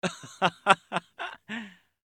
Звук мужского смеха